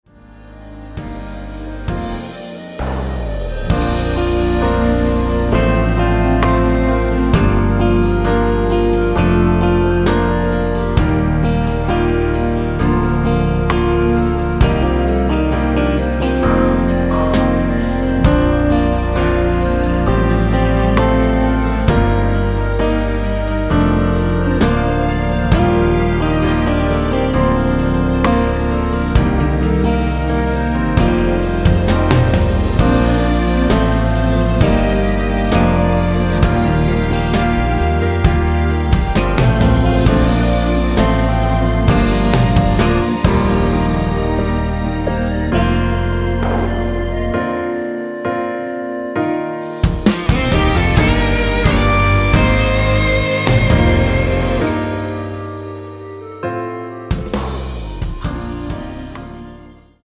원키에서(-3)내린 멜로디와 코러스 포함된 MR입니다.(미리듣기 확인)
Bb